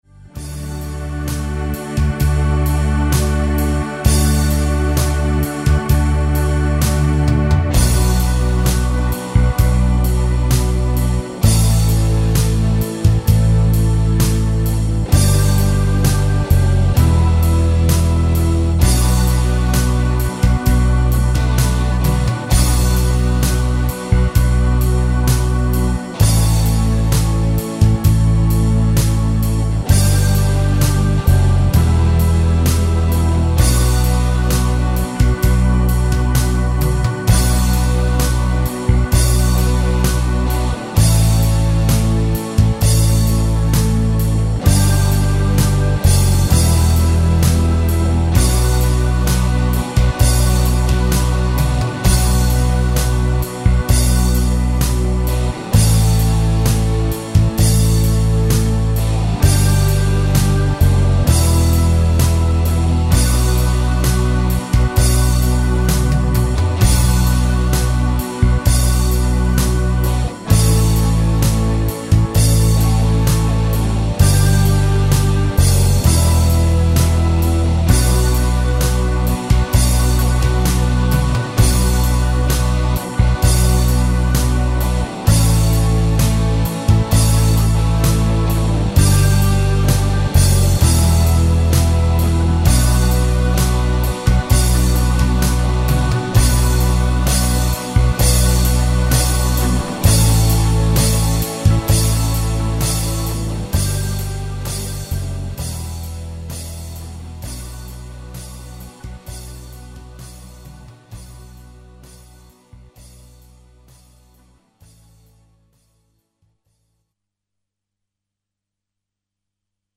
SOLO2 (standard) [130 bpm